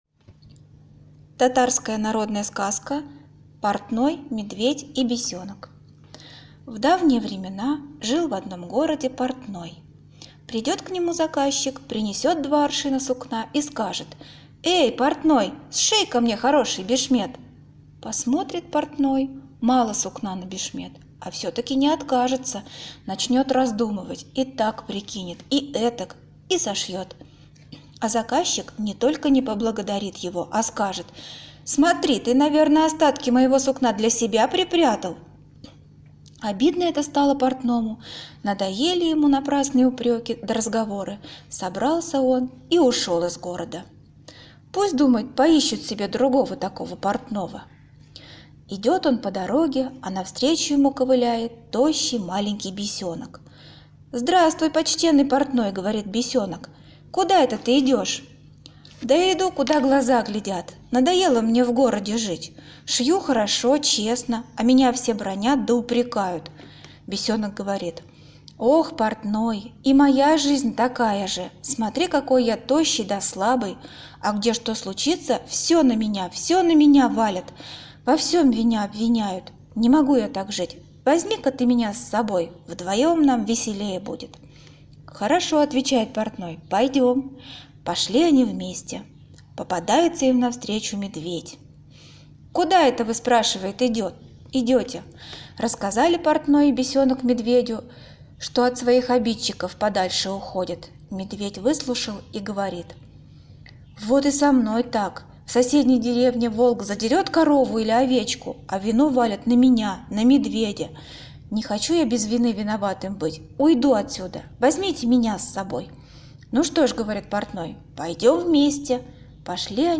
Аудиосказка «Портной, бесенок и медведь»